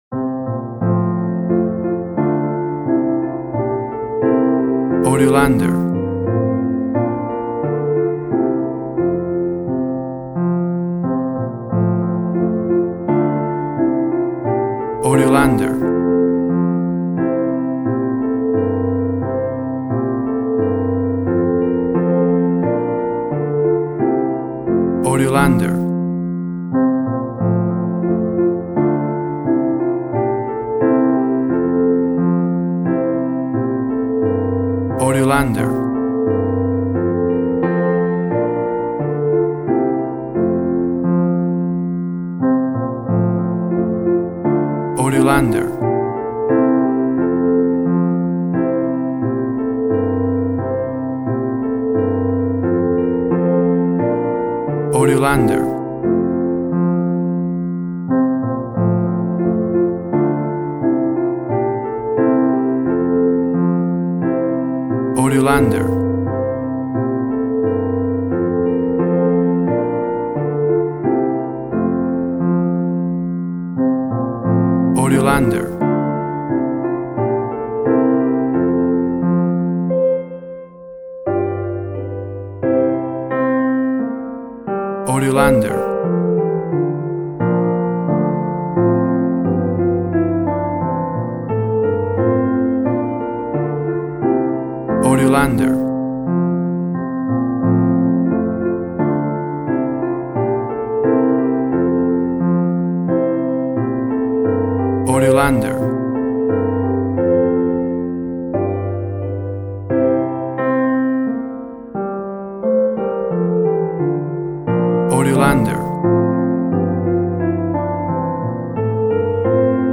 A soft and smooth version of the well know christmas tune
played on a beautiful acoustic piano
WAV Sample Rate 16-Bit Stereo, 44.1 kHz
Tempo (BPM) 89